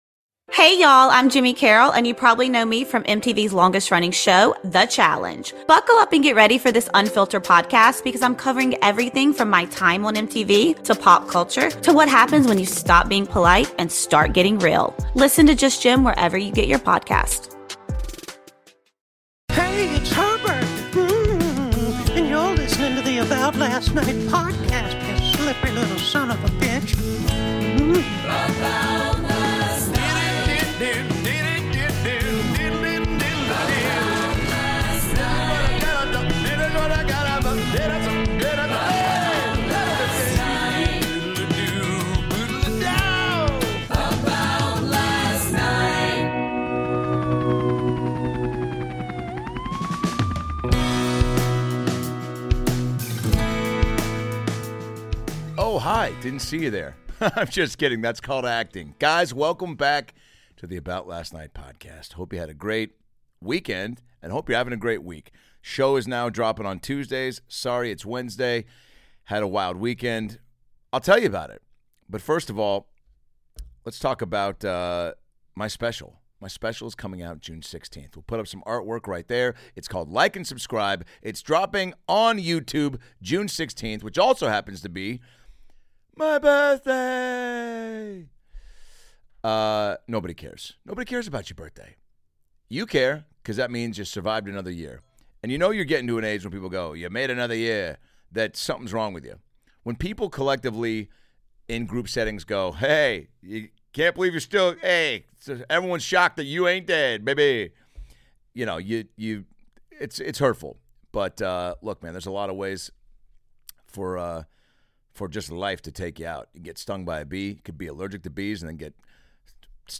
In this Epiosde of About Last Night, Adam Ray discusses seeing Pearl Jam at the Forum in LA, North West as Simba in the Lion King at the Hollywood Bowl and has a chat with comedian Brad Williams!